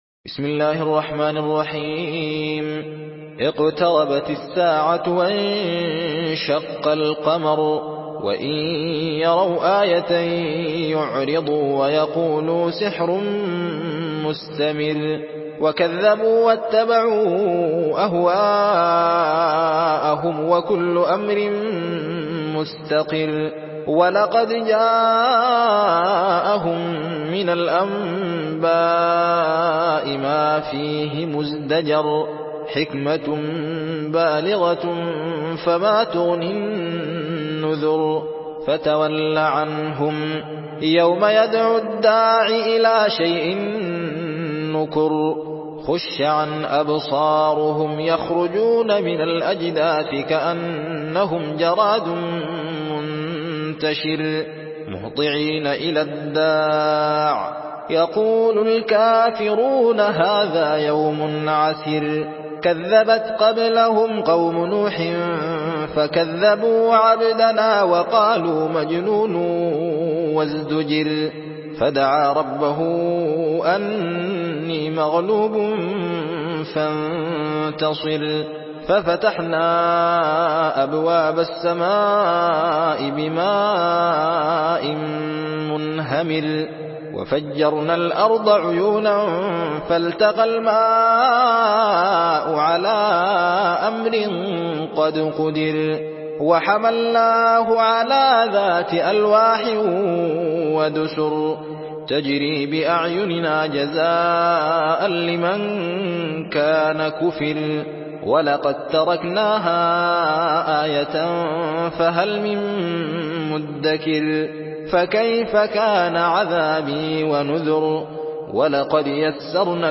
سورة القمر MP3 بصوت الزين محمد أحمد برواية حفص
مرتل حفص عن عاصم